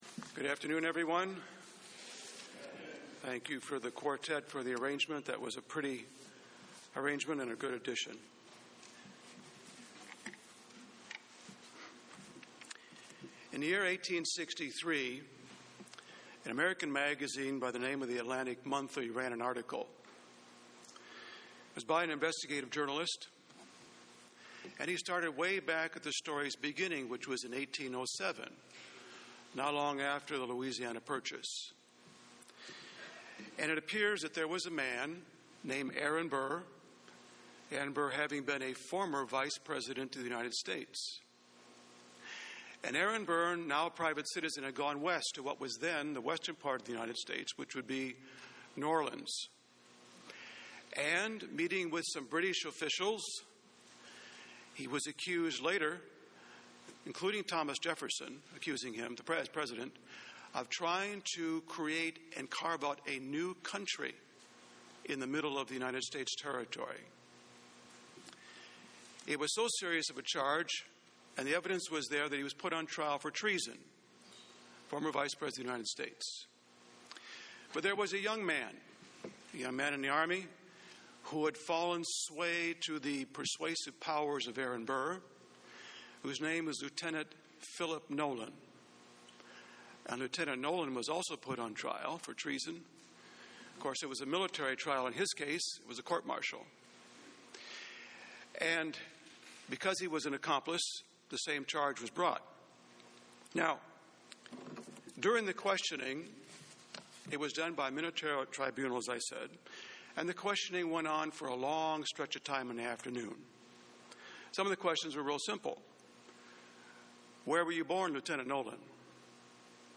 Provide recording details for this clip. Given in Columbus, OH